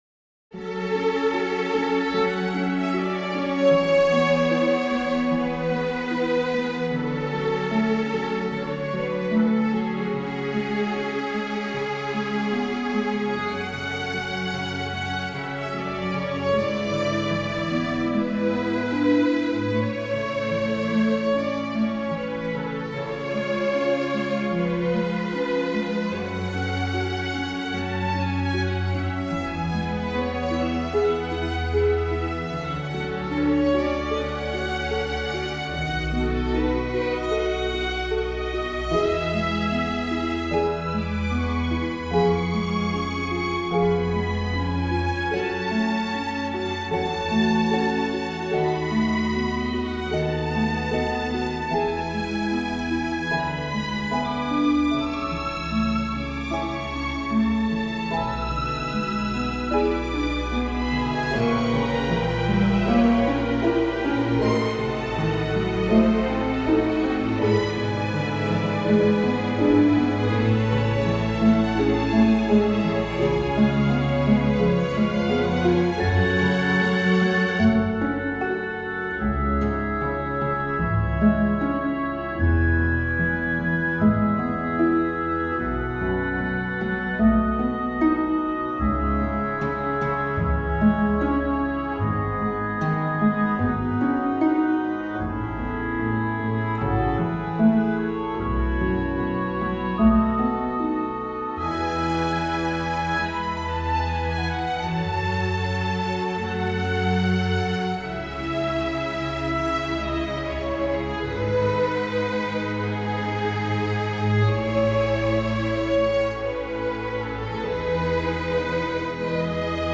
Van de stukken voor orkest is er met behulp van StaffPad een synthetische "weergave" worden gemaakt.
Op.54 No.4 Nocturne Symfonieorkest september 2025 Strijkers, cello solo, harp, houtblazers, trombones en pauken